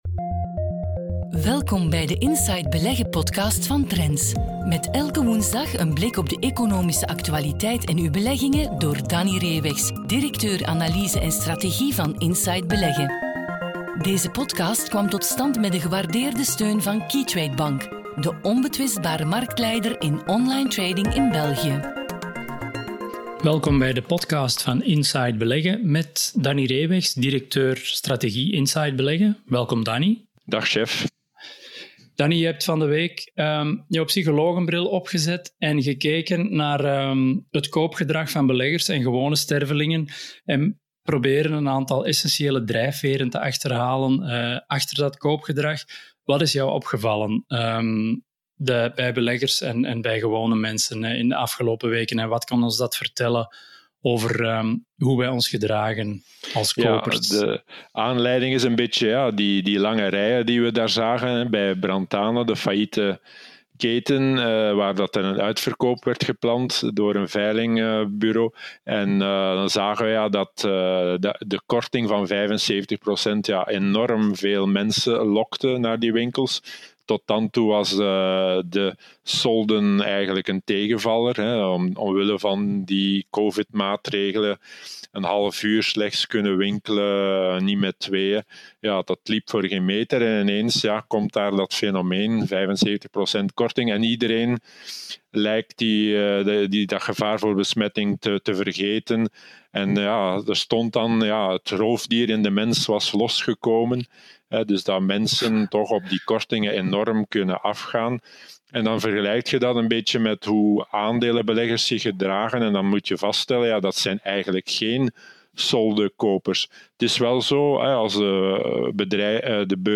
gaat elke woensdag in gesprek met de journalisten van Trends en geeft u achtergrond en analyse voor uw beleggingen. Met wekelijks een inkijk in de recente evoluties op de financieel economische actualiteit en telkens één opmerkelijk aandeel, bedrijf of sector onder de loep genomen.